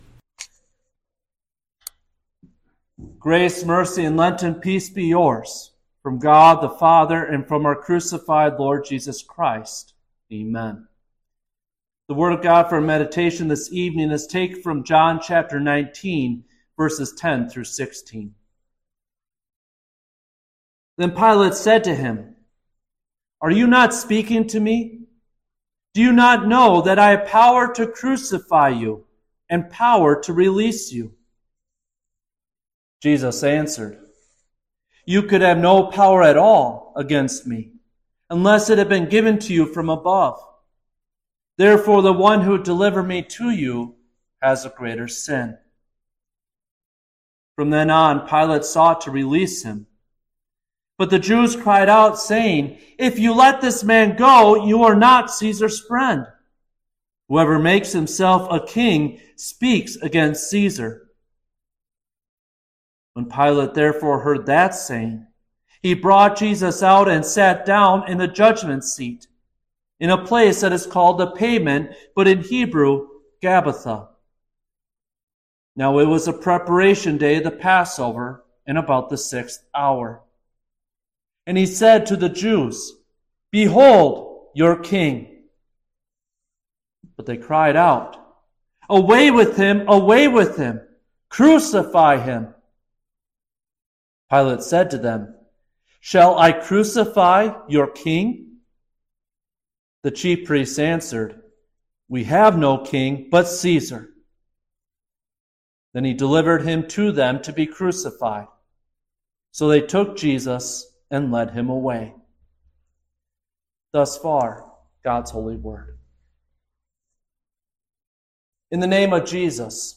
Fifth-Midweek-Lenten-Service.mp3